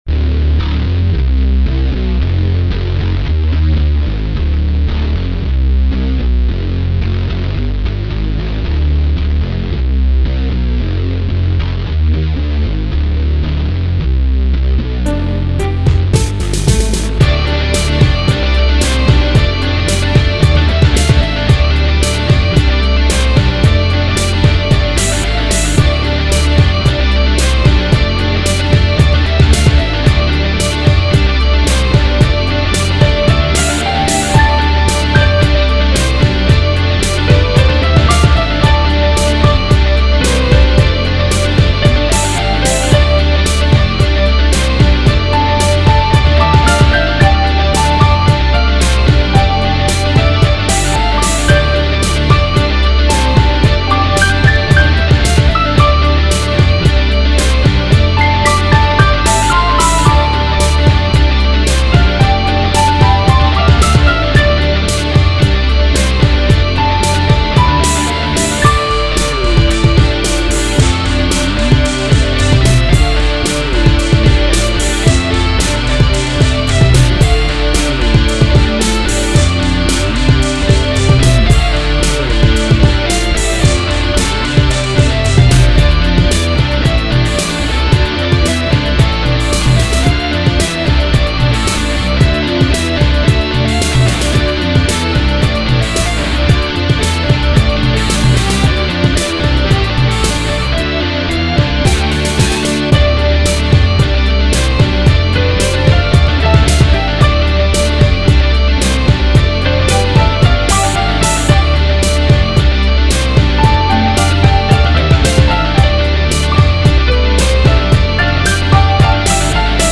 techno industrial trance
That piano instrument sounded strange at first when it is brought in.
(that may have been a weird example but oh well. hahaha) The whole thing sounded really good and was very catchy.
The guitar melody was really cool.